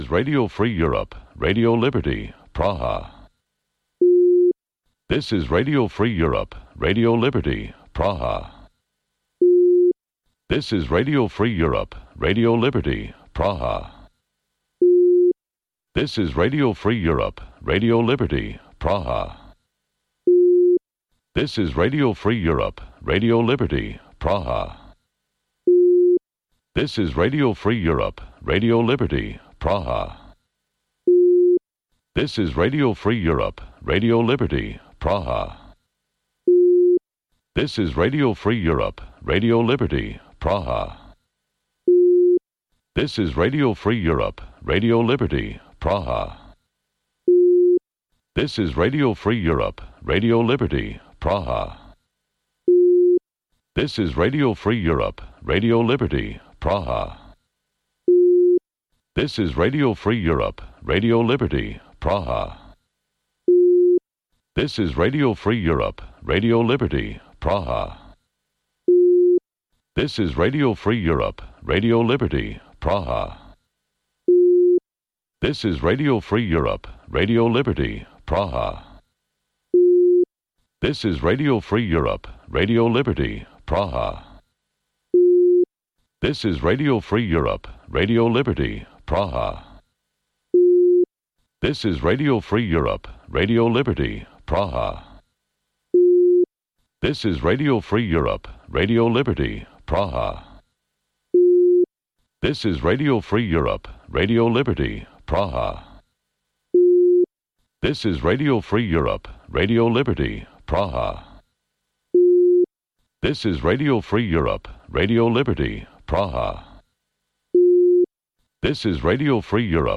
Бул үналгы берүү ар күнү Бишкек убакыты боюнча саат 18:30ден 19:00га чейин обого түз чыгат.